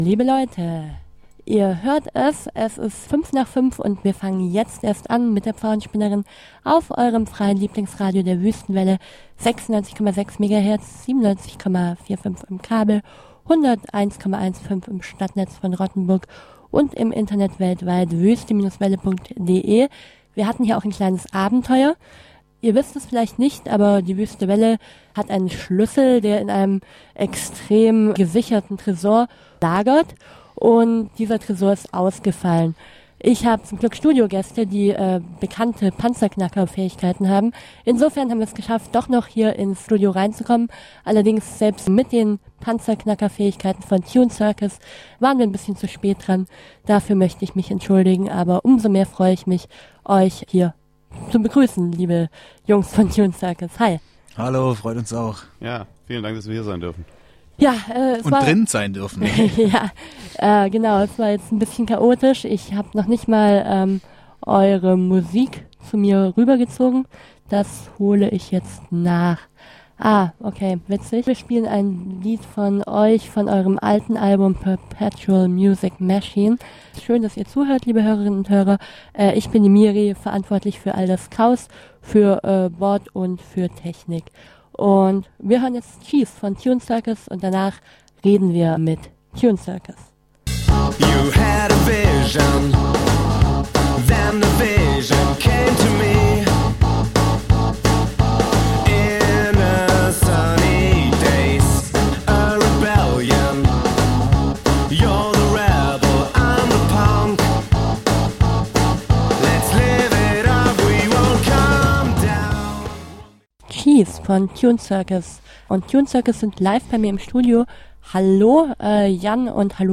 Audio Das Interview mit Tune Circus Download (30,5 MB) tunecircus.mp3